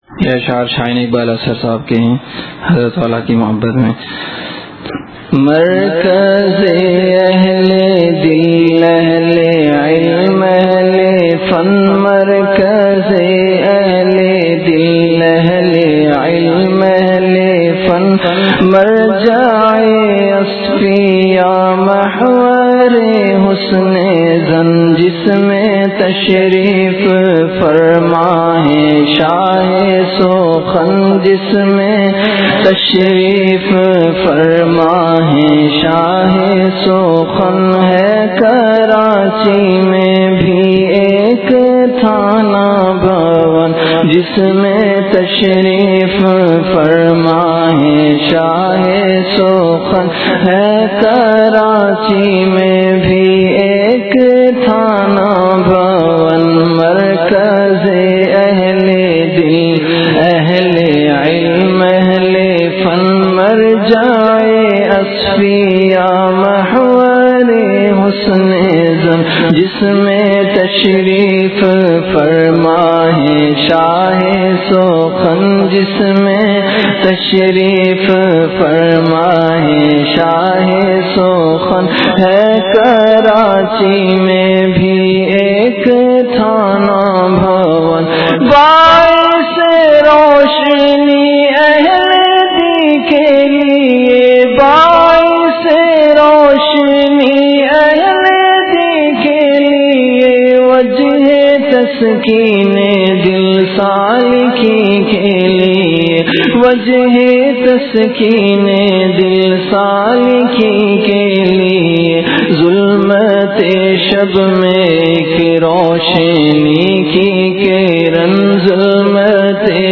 Delivered at Khanqah Imdadia Ashrafia.
Ashaar · Khanqah Imdadia Ashrafia